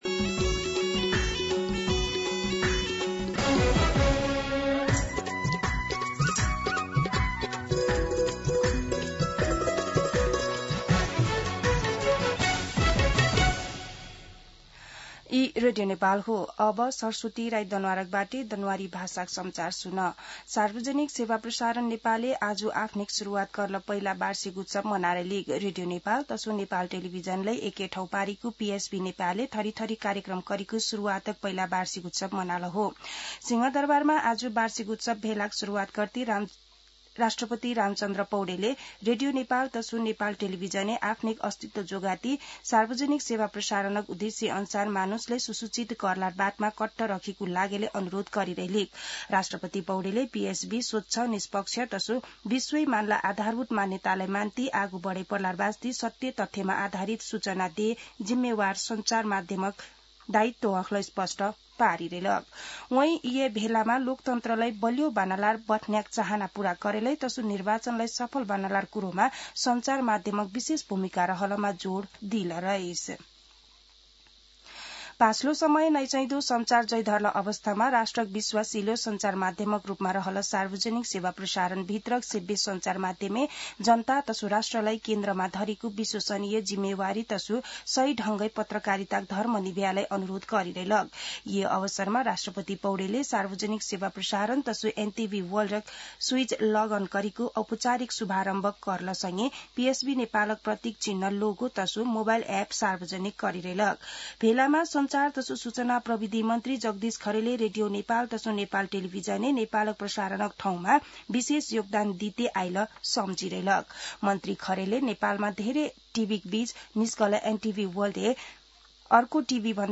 दनुवार भाषामा समाचार : २३ कार्तिक , २०८२
Danuwar-News-07-23.mp3